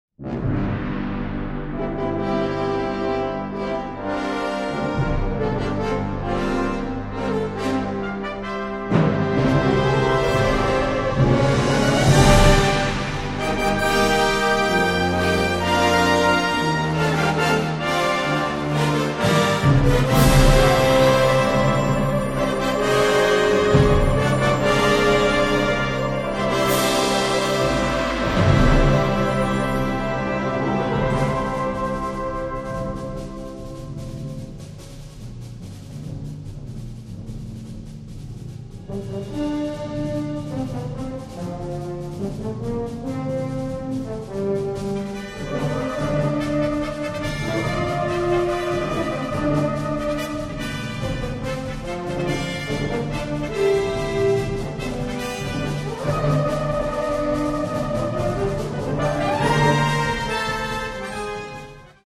short overture